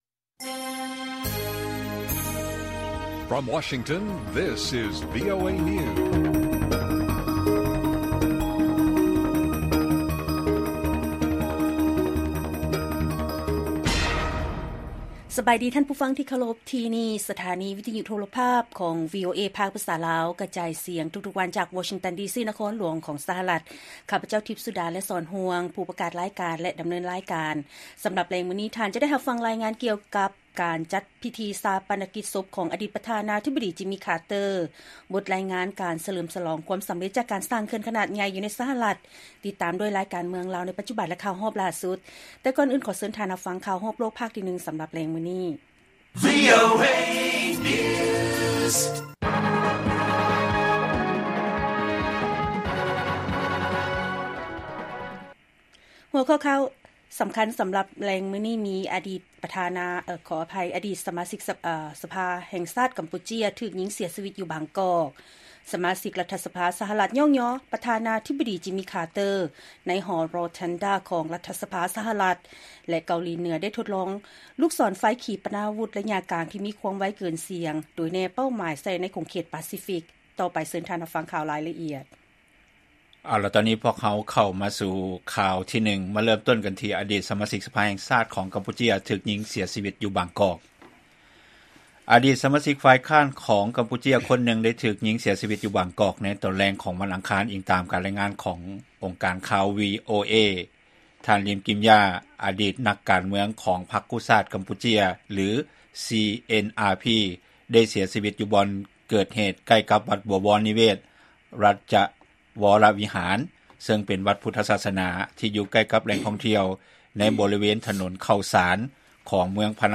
ລາຍການກະຈາຍສຽງຂອງວີໂອເອລາວ: ອະດີດສະມາຊິກສະພາແຫ່ງຊາດຂອງກຳປູເຈຍ ຖືກຍິງເສຍຊີວິດຢູ່ບາງກອກ